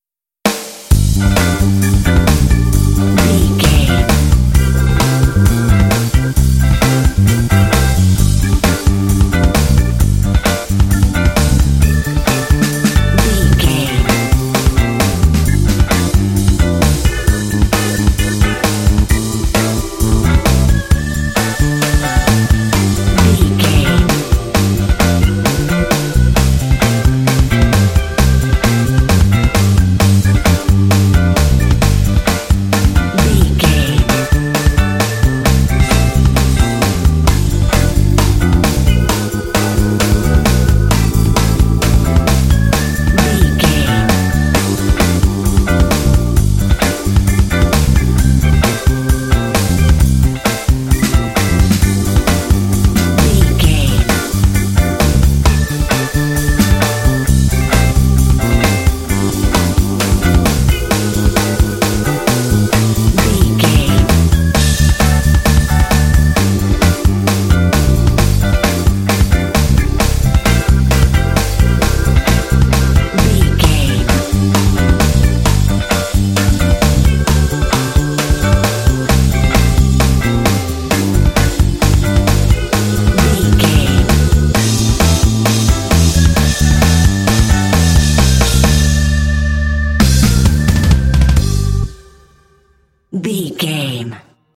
This bluesy track is full of urban energy.
Aeolian/Minor
funky
groovy
energetic
driving
electric guitar
electric organ
bass guitar
drums
blues
jazz